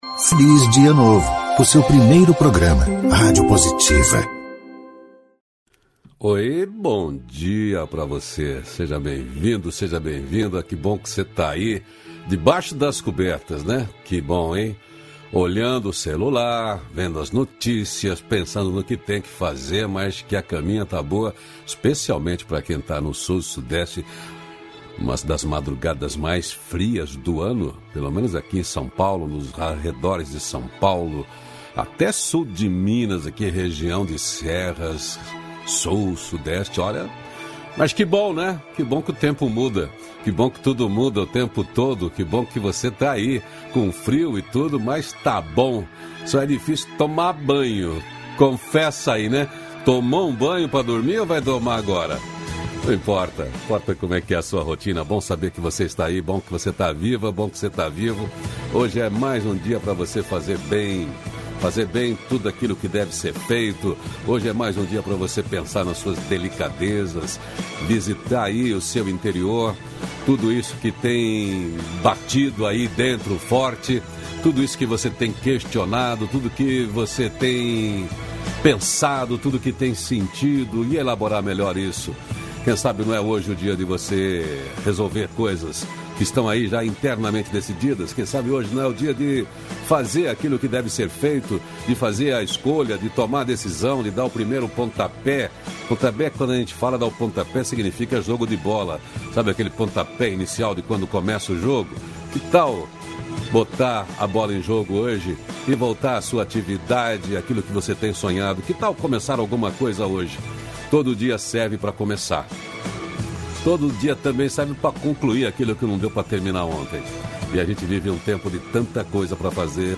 -523FelizDiaNovo-Entrevista.mp3